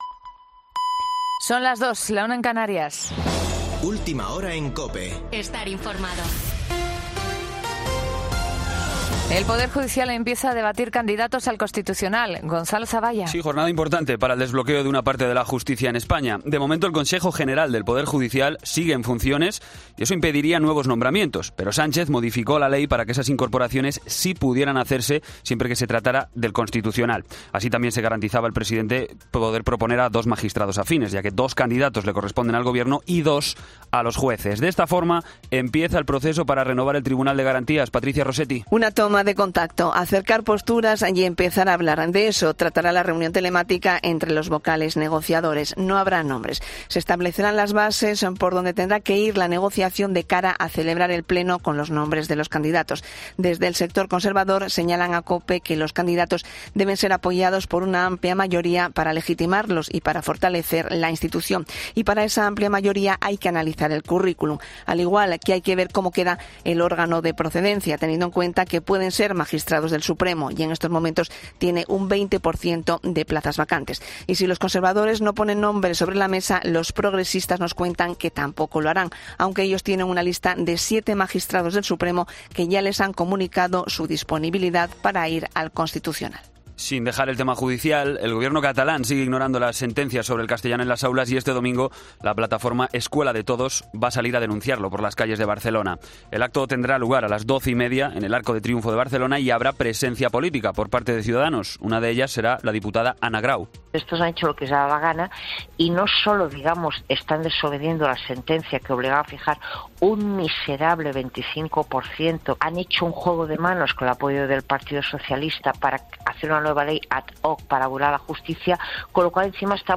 Boletín de noticias COPE del 16 de septiembre a las 02:00 hora
AUDIO: Actualización de noticias Herrera en COPE